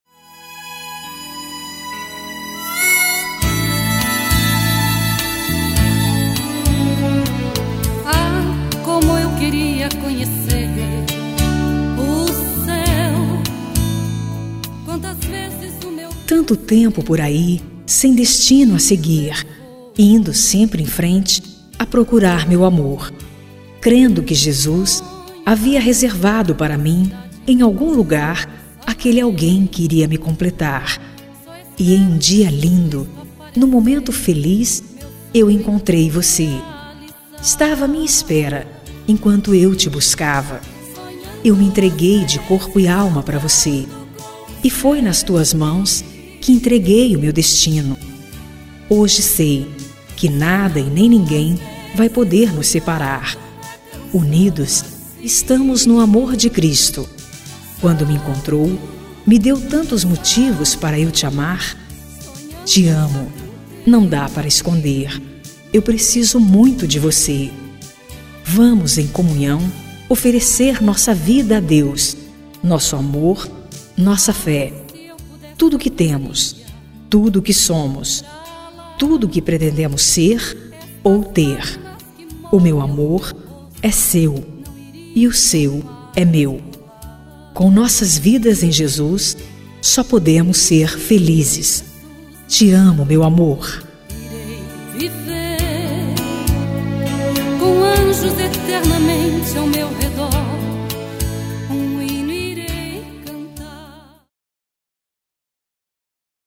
Romântica Evangélica | Voz Feminina